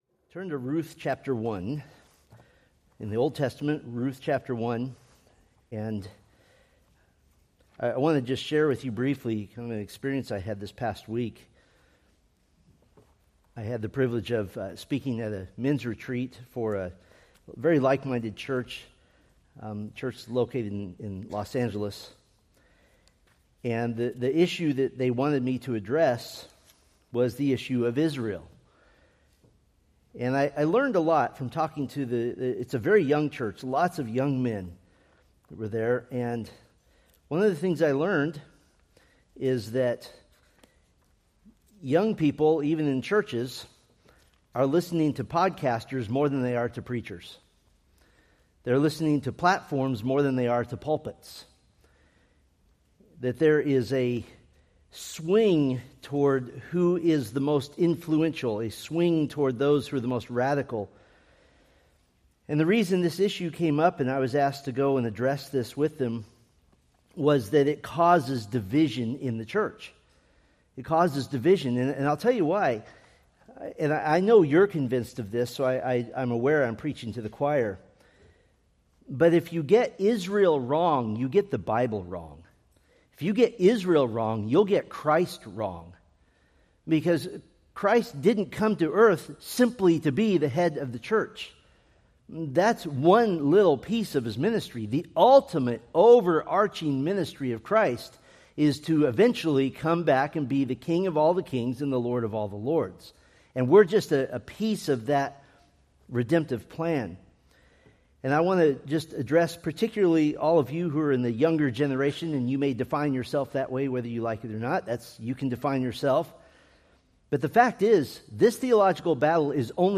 Preached November 2, 2025 from Selected Scriptures